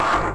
mech_duck.ogg